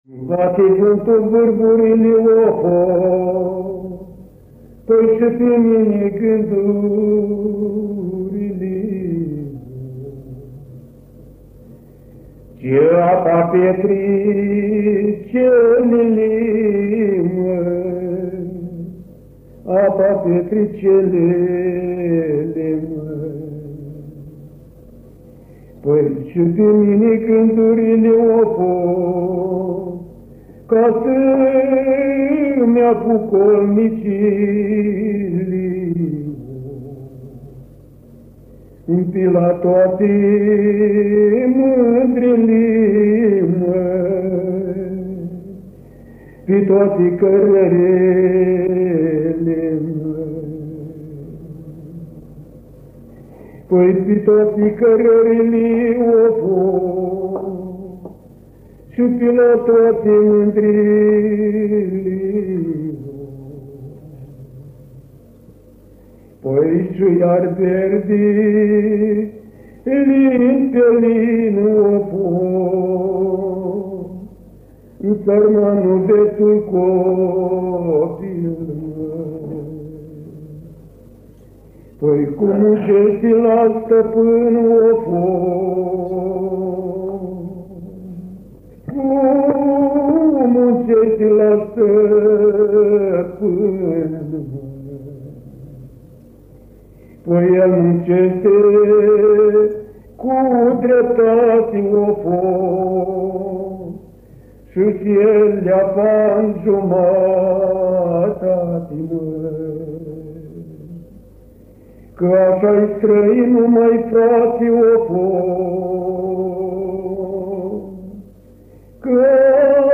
Bate vântul vârfurile, în interpretarea rapsodului popular
înregistrare din anul 1976